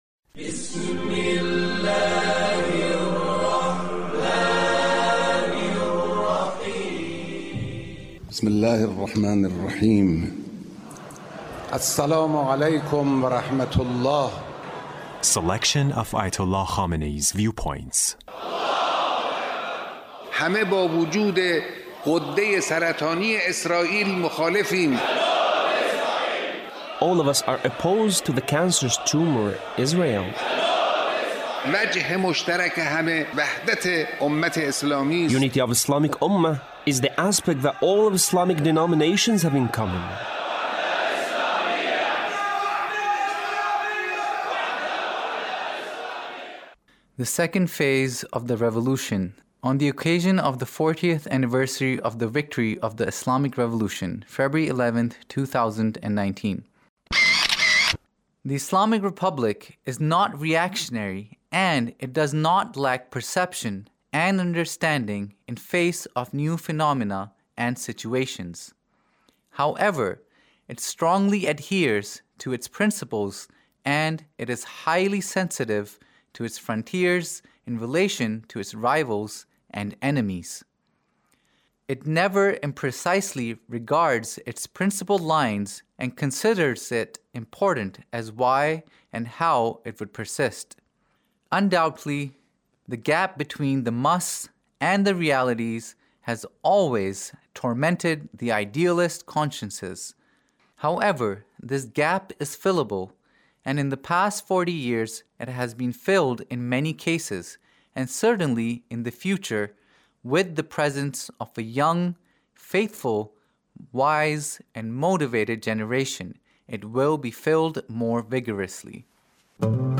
Leader's Speech (1872)